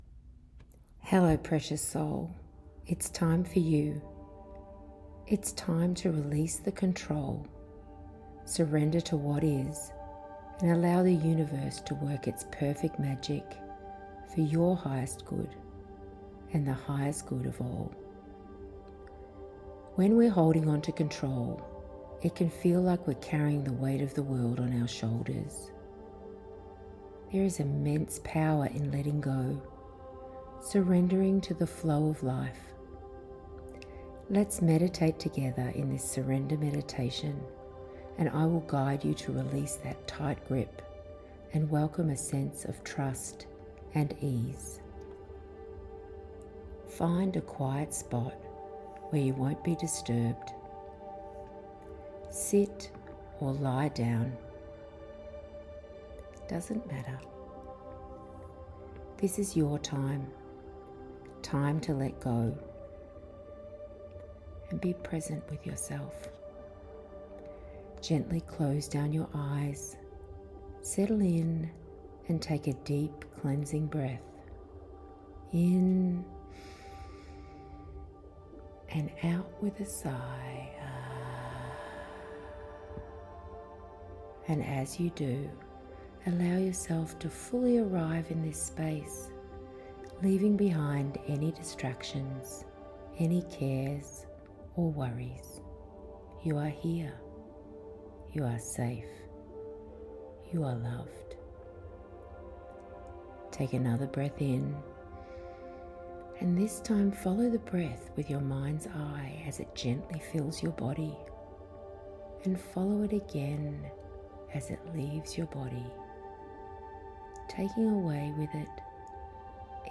Surrender Meditation Practice When we’re holding onto control, it can feel like we’re carrying the weight of the world on our shoulders. But there’s immense power in letting go—surrendering to the flow of life. Let’s meditate together in this Surrender Meditation and I will guide you to release that tight grip and welcome a sense of trust and ease.